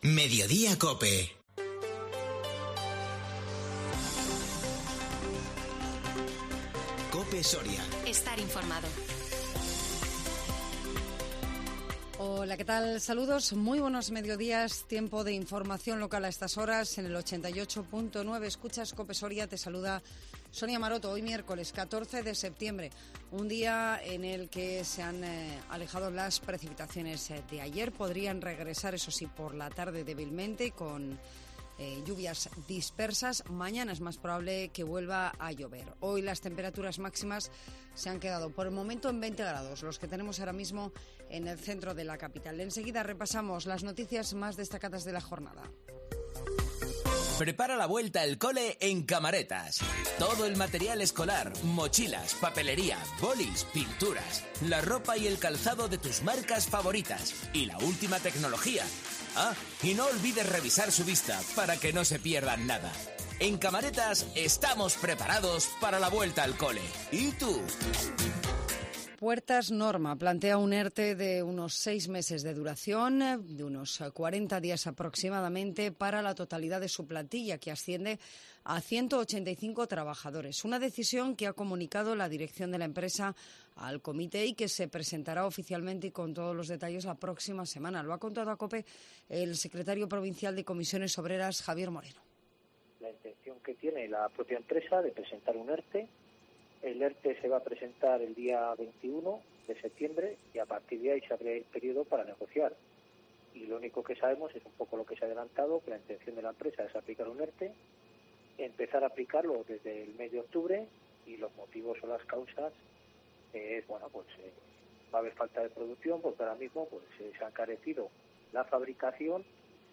INFORMATIVO MEDIODÍA COPE SORIA 14 SEPTIEMBRE 2022